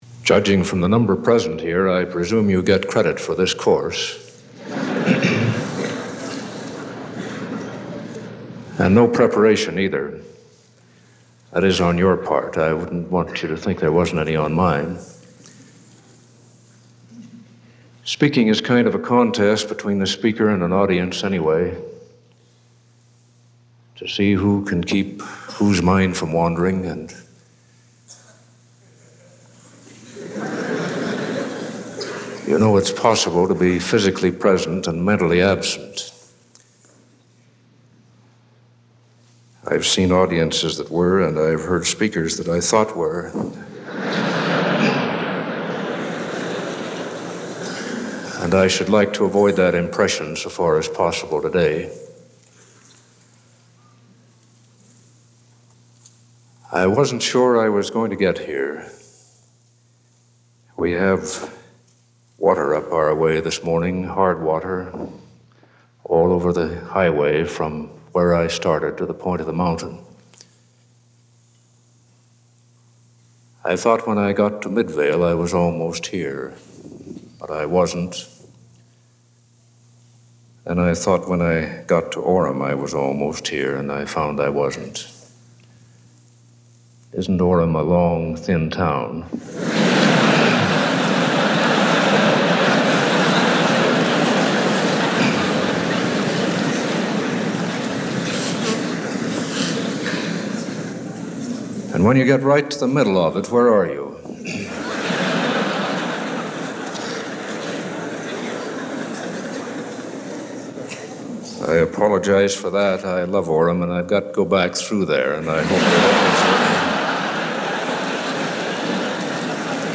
Richard L. Evans was a member of the Quorum of Twelve Apostles of The Church of Jesus Christ of Latter-day Saints when he delivered this devotional address at Brigham Young University on February 14, 1956.